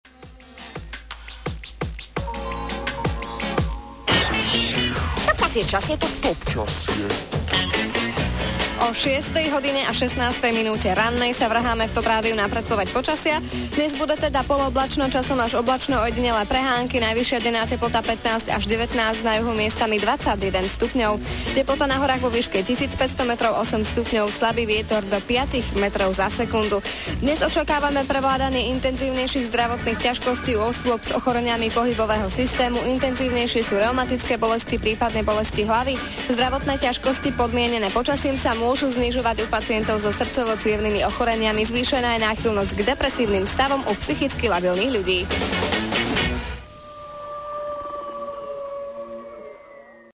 V kratučkom texte úplne odignorovala päť mäkkých slabík.
Má príjemný hlas, tvrdá výslovnosť sa v iných vstupoch neprejavila a tak snáď išlo len o jednorázové pošmyknutie.